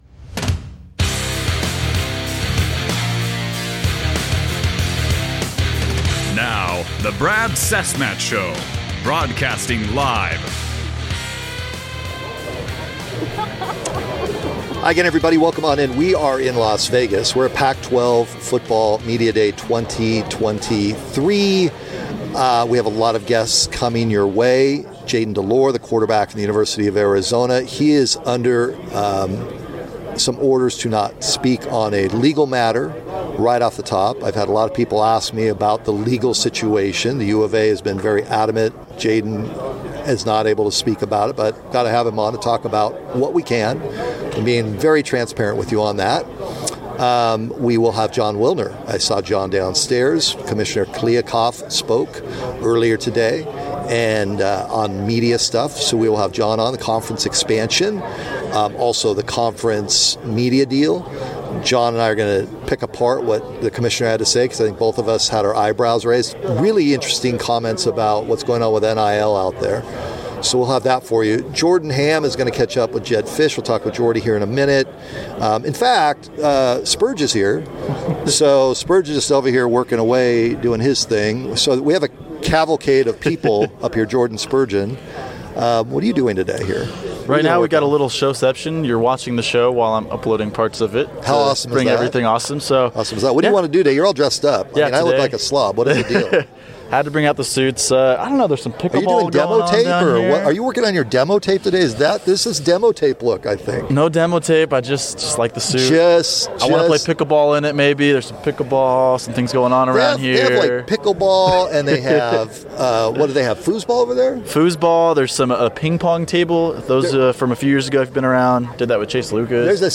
July 21st Podcast: Live from Pac-12 Media Day 2023